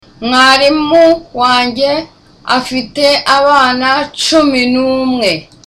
(With a low voice.)